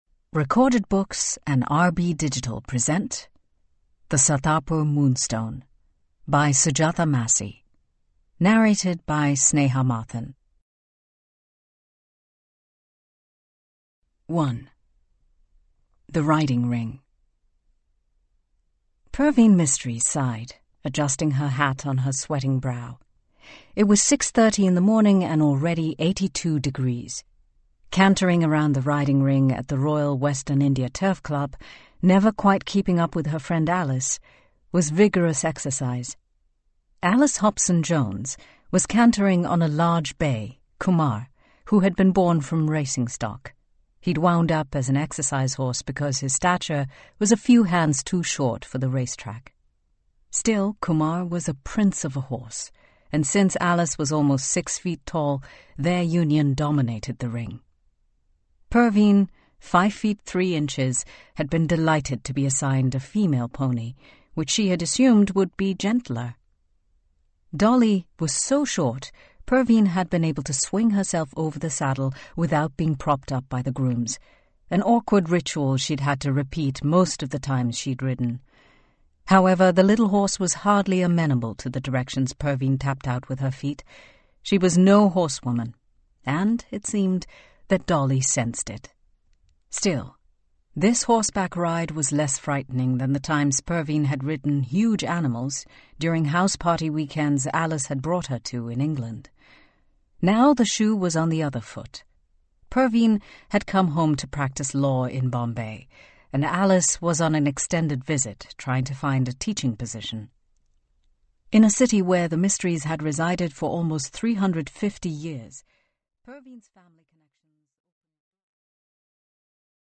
(OverDrive MP3 Audiobook, OverDrive Listen)
Unabridged